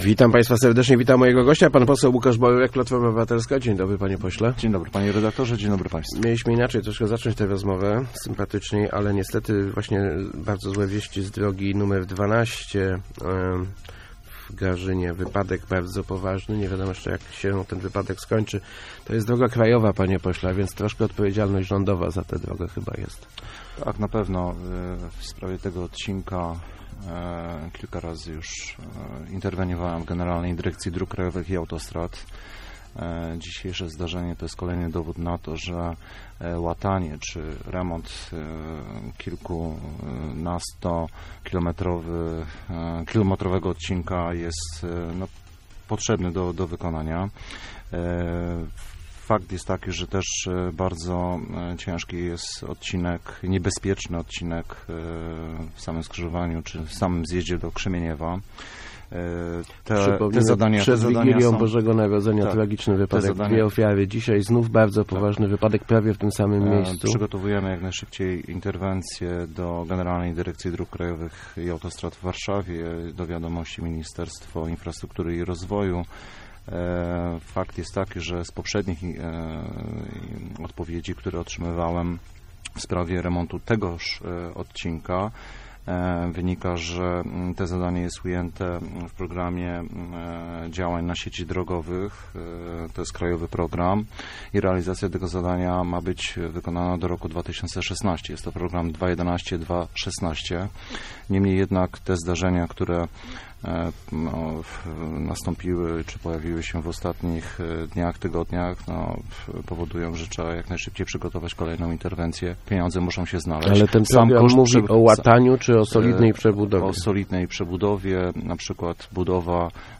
Skłaniam się ku zaangażowaniu w samorządową kampanię wyborczą - mówił w Rozmowach Elki poseł PO Łukasz Borowiak. Jak dodał, ma już kilka propozycji dotyczących startu, jednak póki co nie zdradził, o jakie ugrupowania i jakie samorządy chodzi.